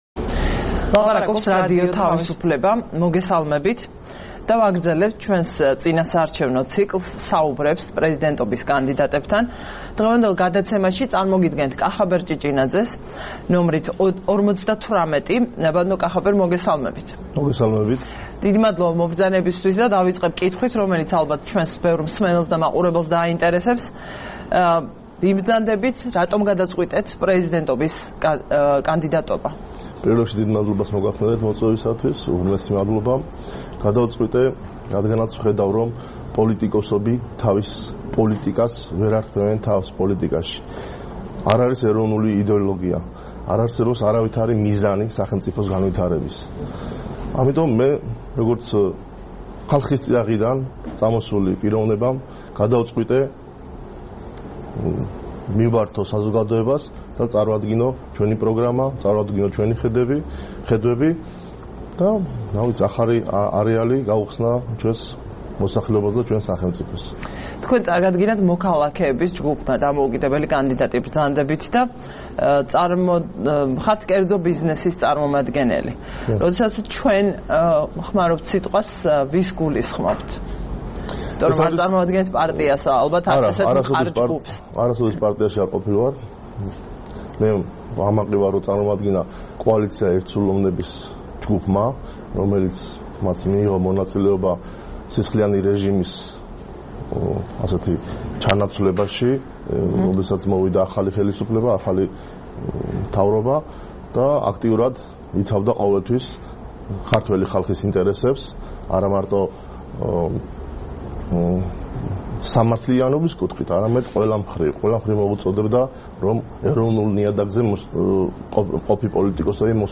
საუბარი პრეზიდენტობის კანდიდატთან